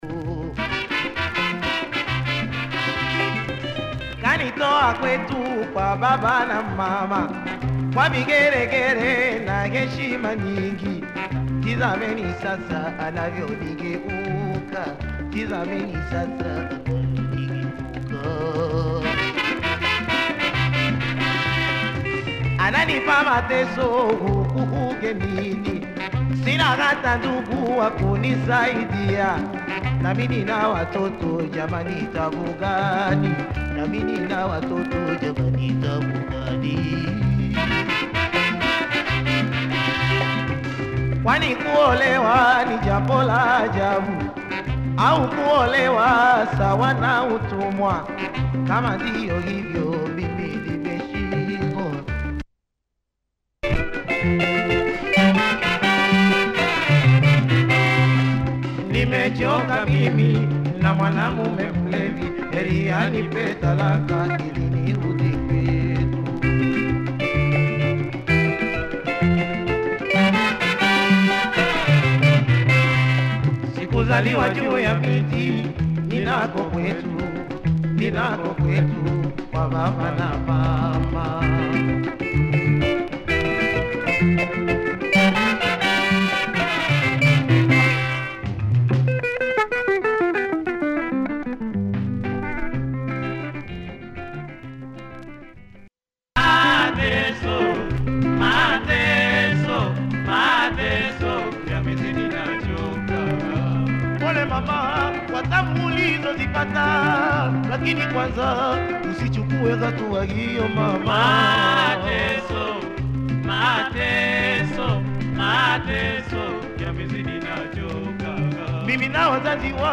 super guitar and lovely vocals too